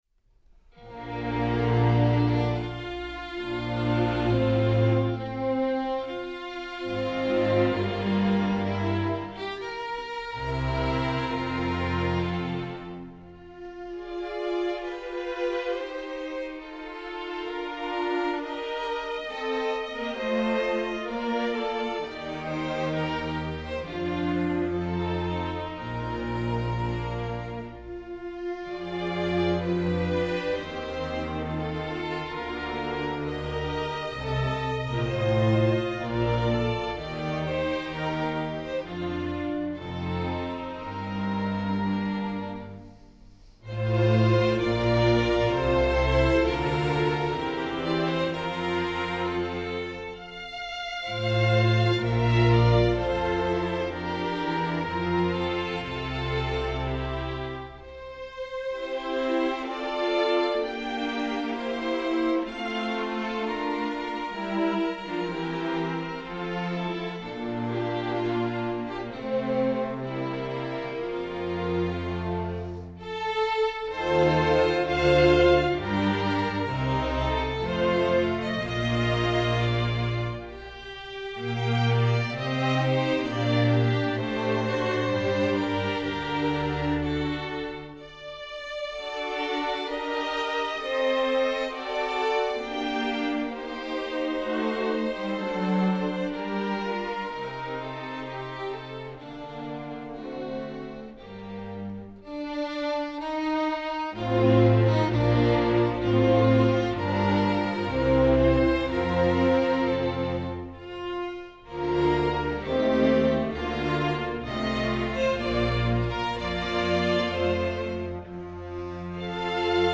Instrumentation: string orchestra
classical